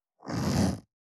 425,ジッパー,チャックの音,洋服関係音,ジー,バリバリ,
ジッパー効果音洋服関係